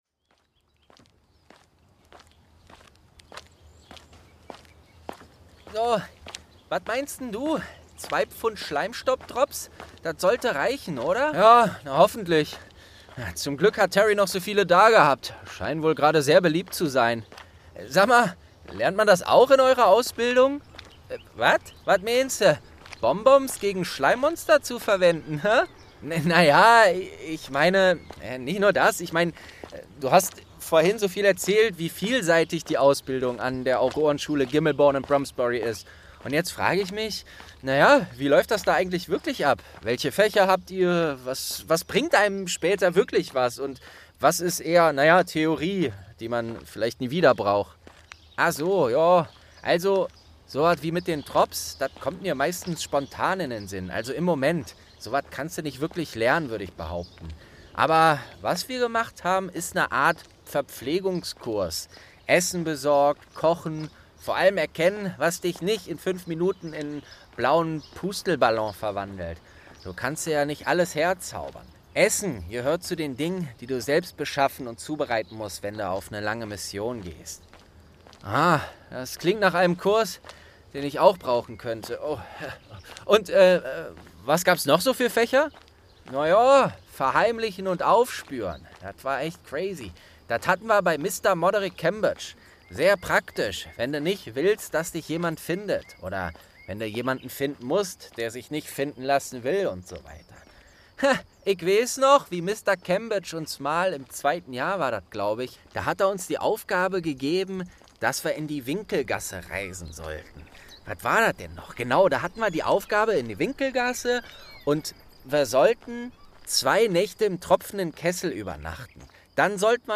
30. Auroren-Geschichten & ein Brief von Percy| St. 2 ~ Geschichten aus dem Eberkopf - Ein Harry Potter Hörspiel-Podcast Podcast